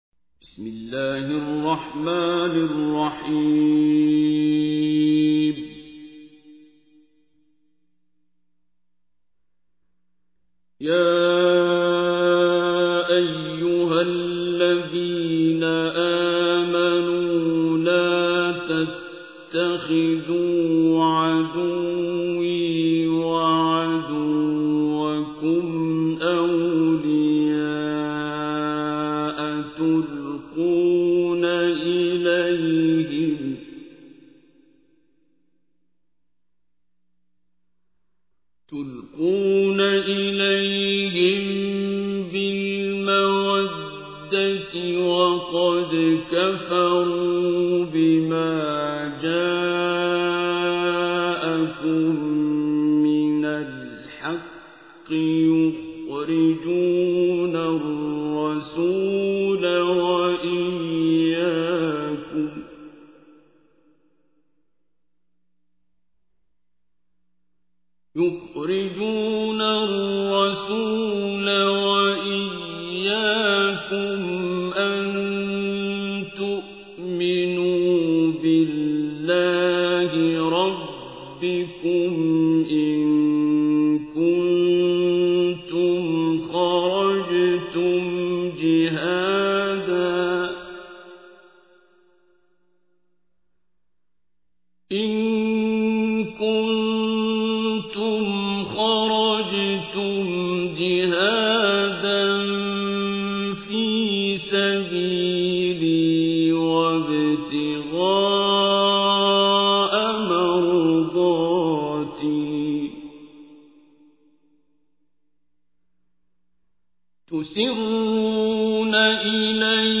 تحميل : 60. سورة الممتحنة / القارئ عبد الباسط عبد الصمد / القرآن الكريم / موقع يا حسين